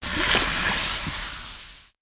liquid_burnshort.wav